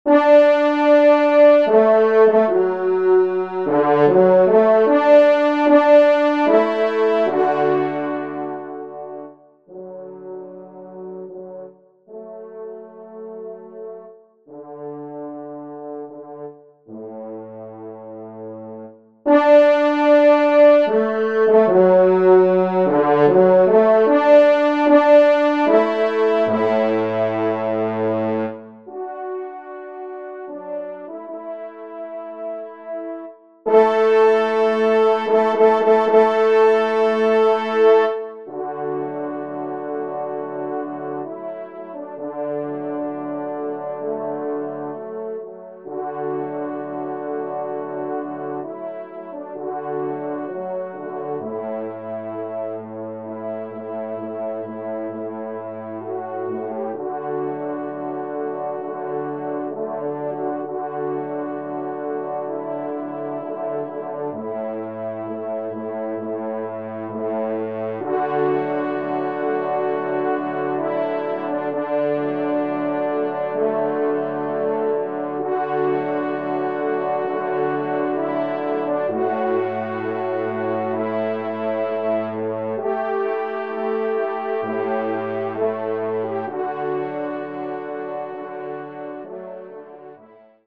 Genre :  Divertissement pour Trompes ou Cors en Ré
4e Trompe